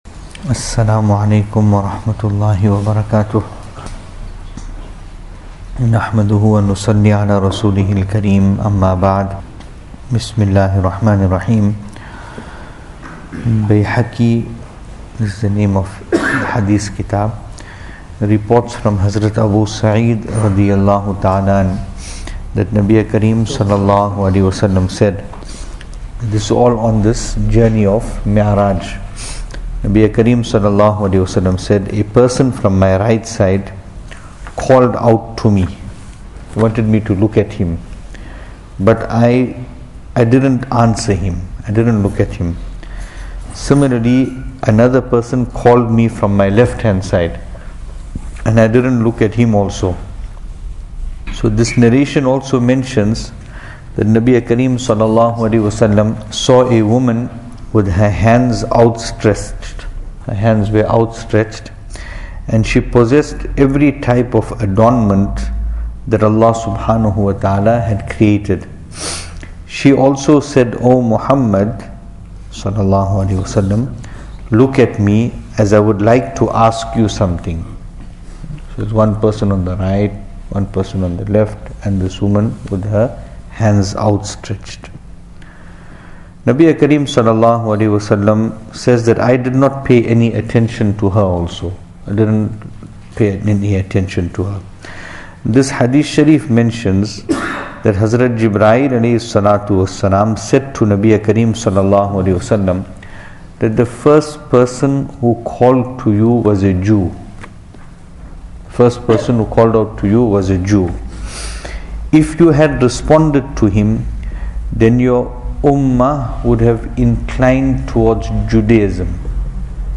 Venue: Masjid Taqwa, Pietermaritzburg | Series: Seerah Of Nabi (S.A.W)